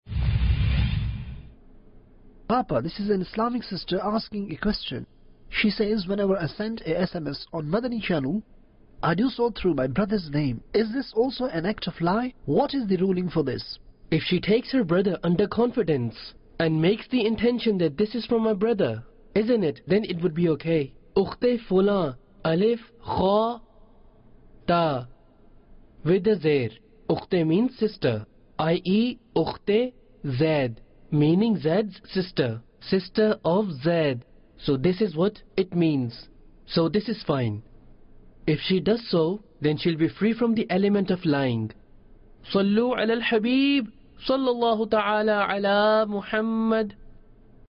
Is It A Lie That An Islamic Sister Sends A Message By Using Tha Name Of Mahram? - English Dubbing Jul 2, 2021 MP3 MP4 MP3 Share Is It A Lie That An Islamic Sister Sends A Message By Using Tha Name Of Mahram?